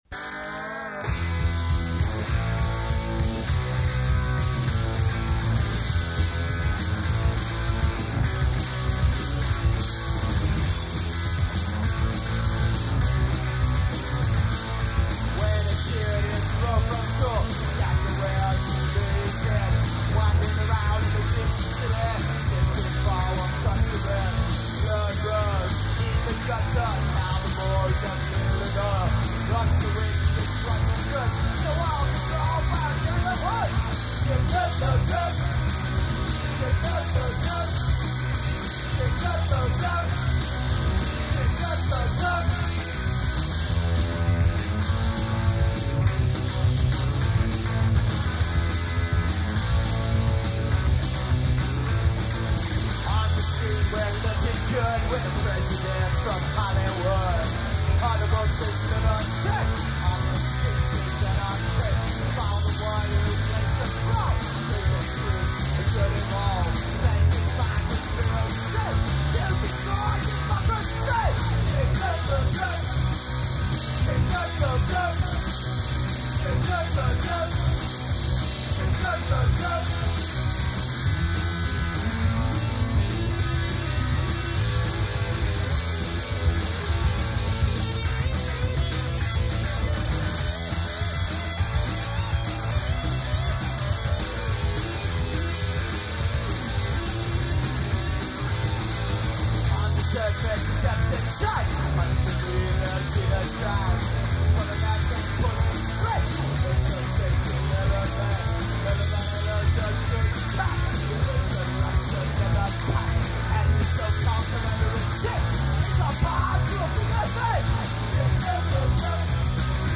Ann Arbor hardcore
broken guitar strings, HEAVY drums and intense vocals.
bass
Studio OUT TAKES from a session around 1983.